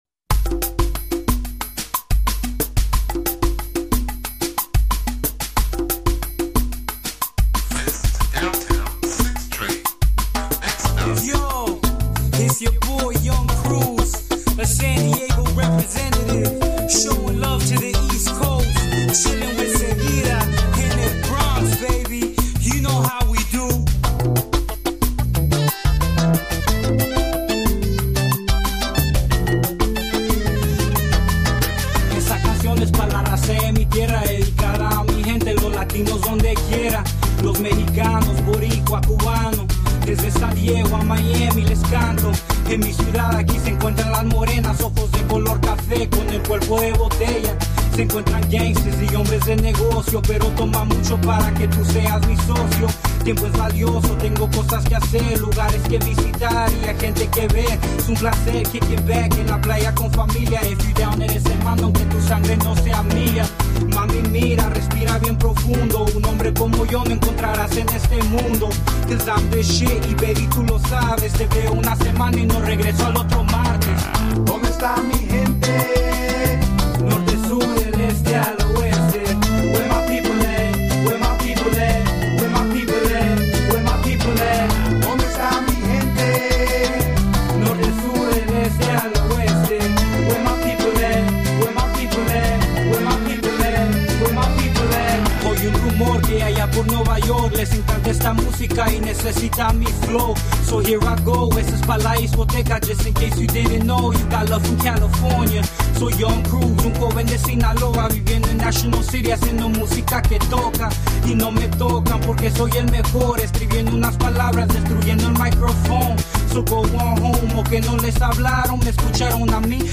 HOT NY LATIN ROCK HIP HOP STYLE